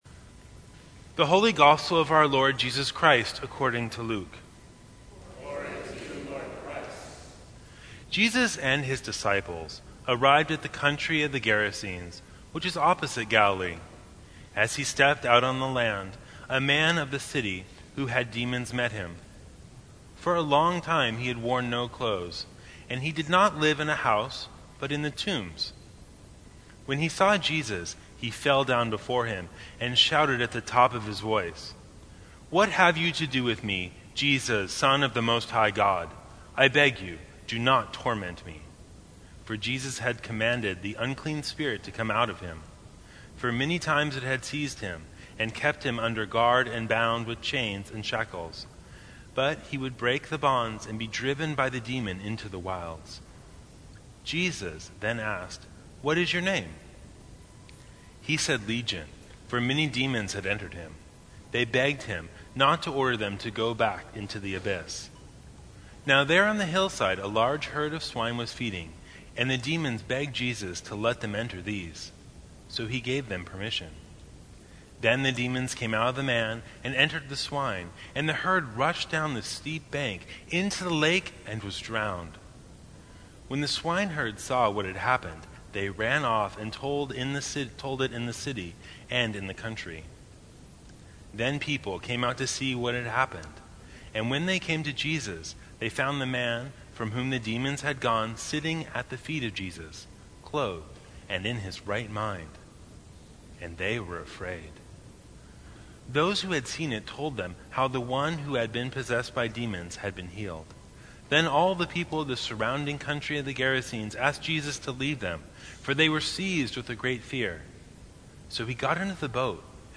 Sermons from St. Cross Episcopal Church The God Who Customizes Jun 26 2019 | 00:11:57 1x 00:00 / 00:11:57 Subscribe Share Apple Podcasts Spotify Overcast RSS Feed Share Link Embed